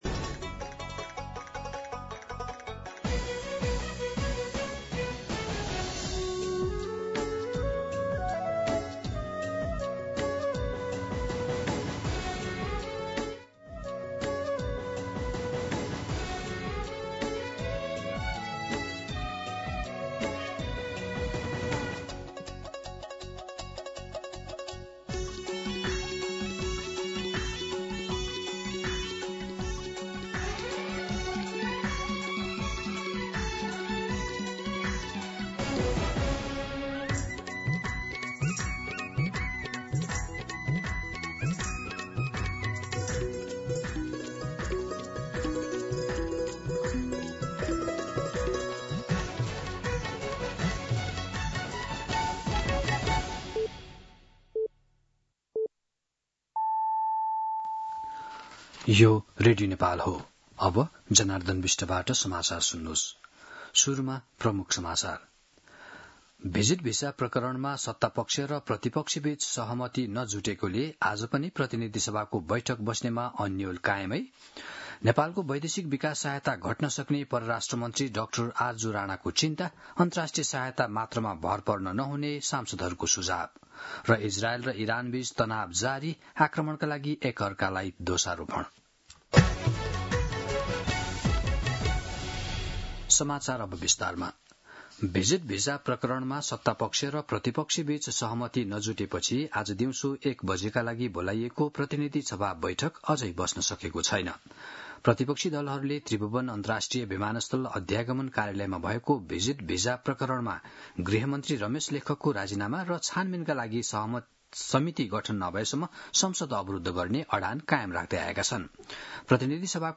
An online outlet of Nepal's national radio broadcaster
दिउँसो ३ बजेको नेपाली समाचार : ३० जेठ , २०८२